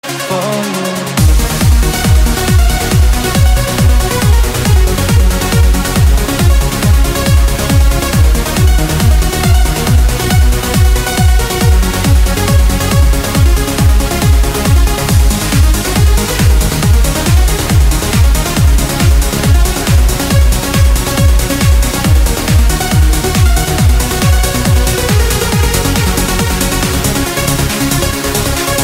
Kategorie Elektroniczne